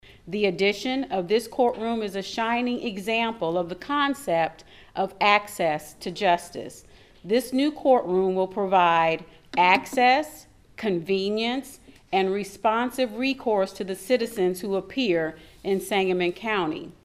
Illinois Supreme Court Justice Lisa Holder White said the new courtroom follows a commitment to serving citizens: